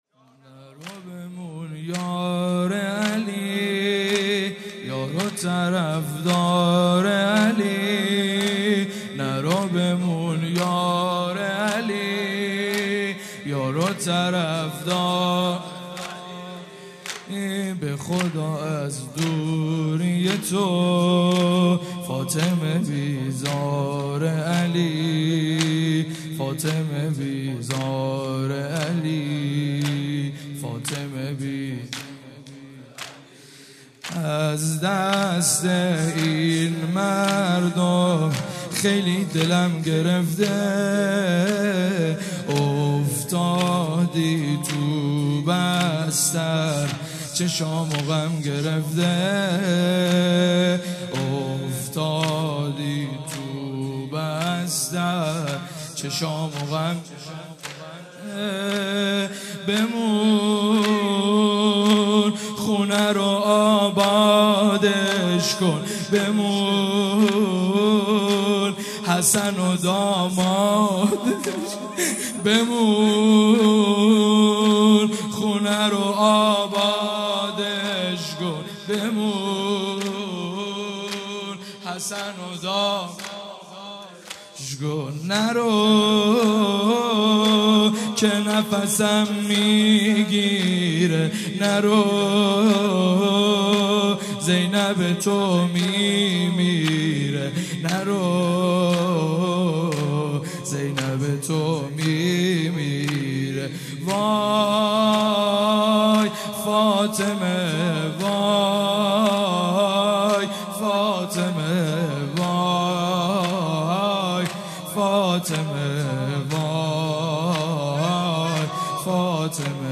شهادت حضرت زهرا (س) | ۲۴ بهمن ۱۳۹۵